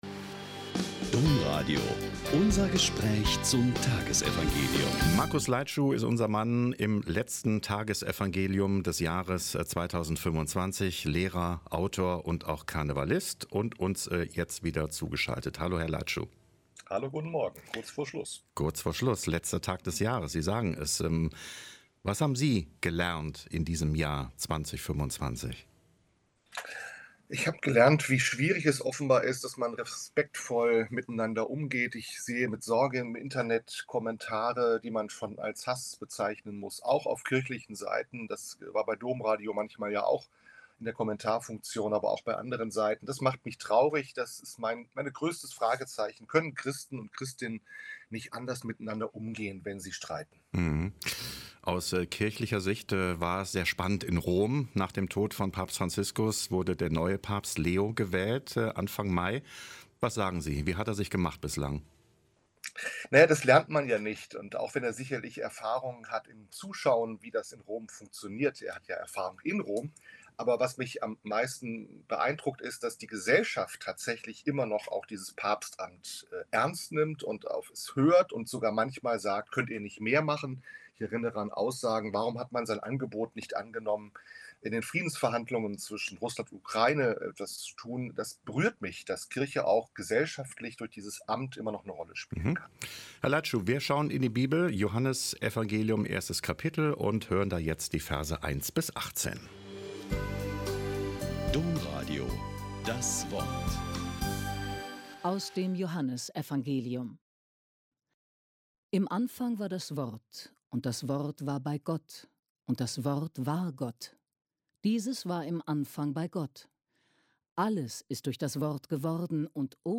Joh 1,1-18 - Gespräch